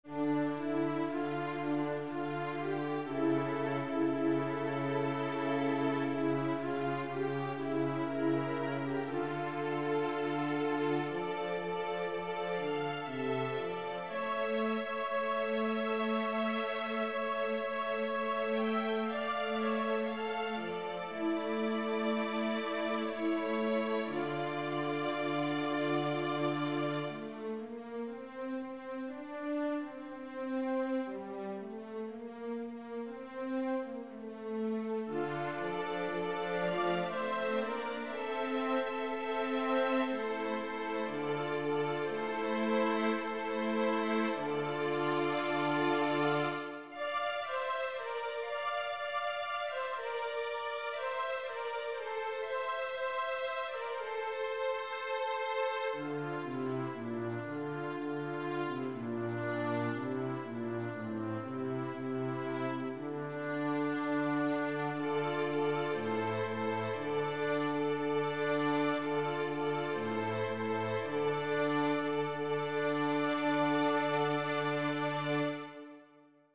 Number of voices: 4vv Voicing: SATB Genre: Sacred
Language: Latin Instruments: A cappella